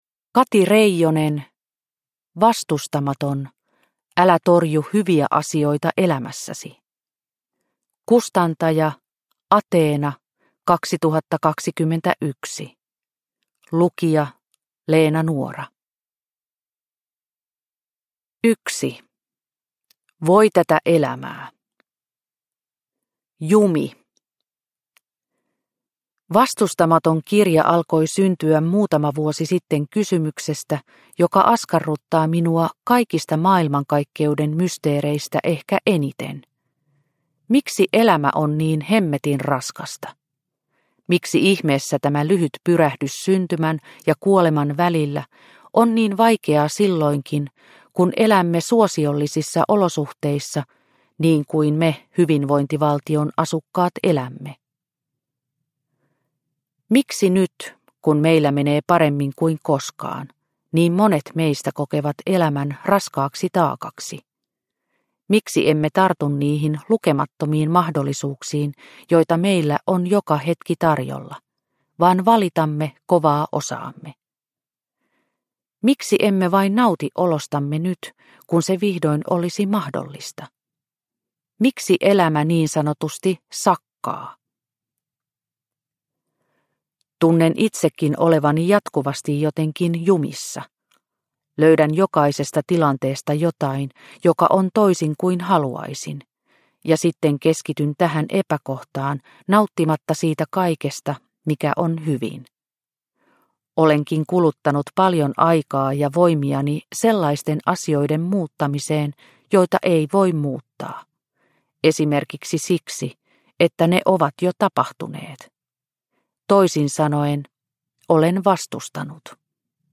Vastustamaton – Ljudbok – Laddas ner